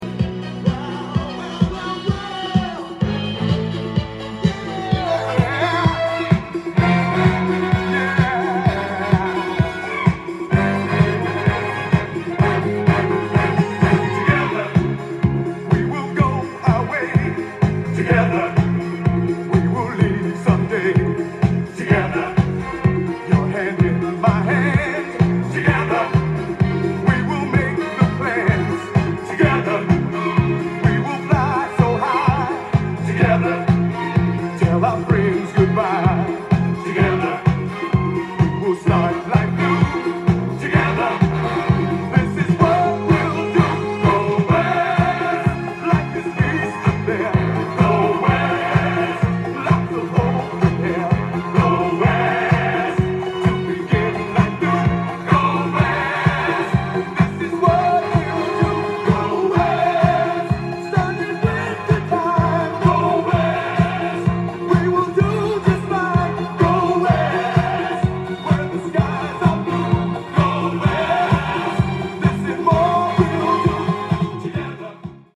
店頭で録音した音源の為、多少の外部音や音質の悪さはございますが、サンプルとしてご視聴ください。
音が稀にチリ・プツ出る程度